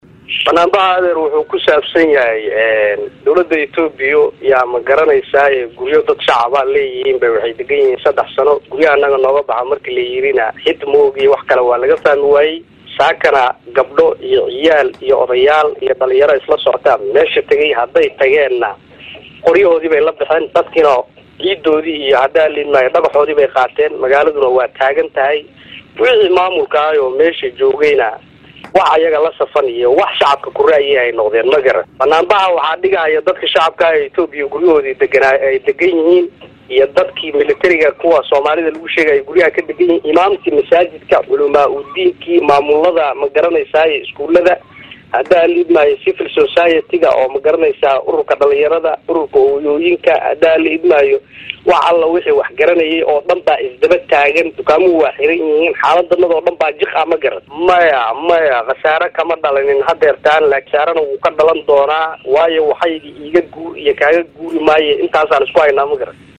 Qaar ka mid ah Odayaasha ku nool Magaalada Buurdhuubo oo la hadlay warbaahinta hadaladooda waxaa ka mid.